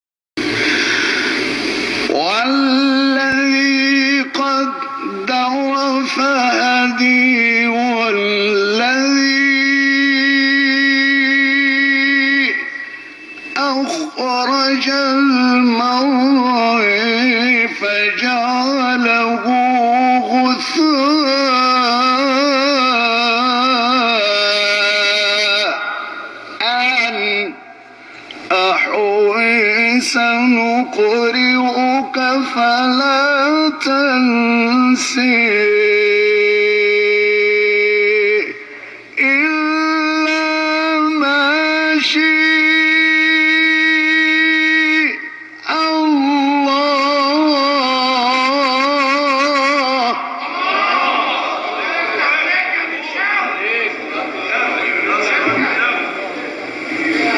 گروه فعالیت‌های قرآنی: مقاطع صوتی دلنشین از قراء بین‌المللی جهان اسلام را می‌شنوید.